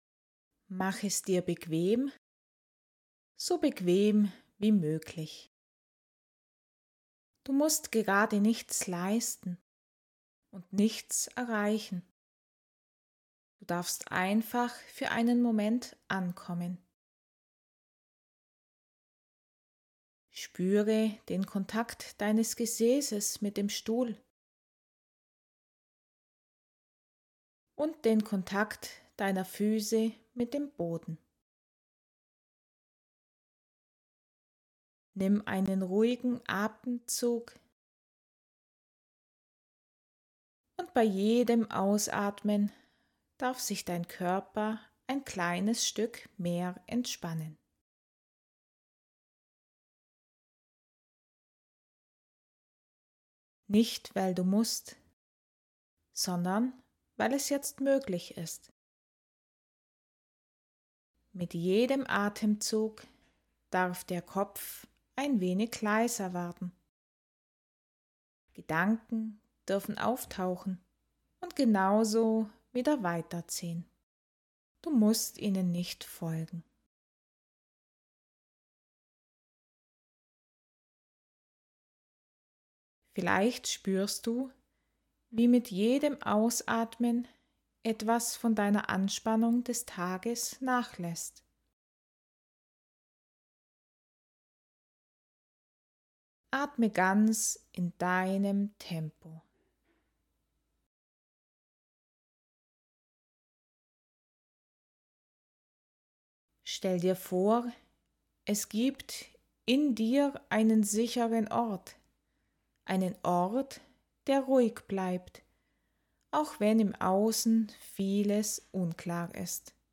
Staffel 4 – Hypnose & Meditation zur Tiefenentspannung